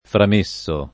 frammettere [framm%ttere] v.; frammetto [framm%tto] — coniug. come mettere; part. pass. frammesso [framm%SSo], anche aggettivo — anche framettere, coniug. sim., col part. pass. framesso [